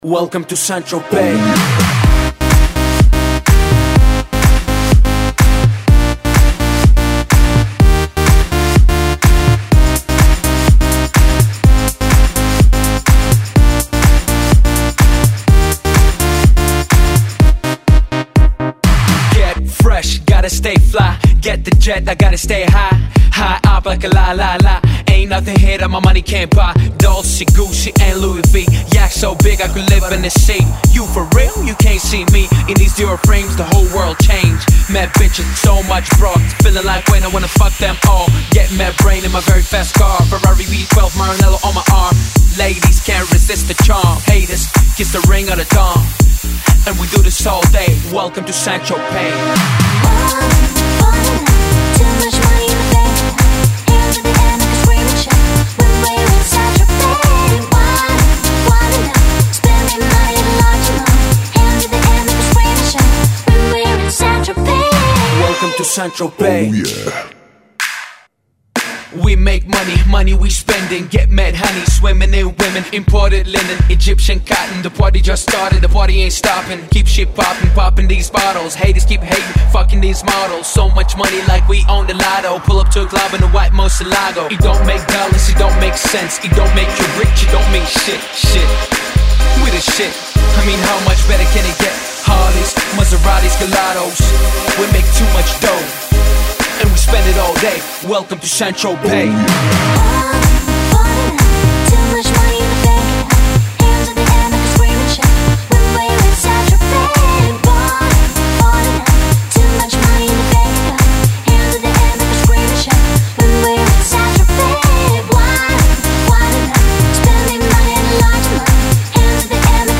House.
club anthems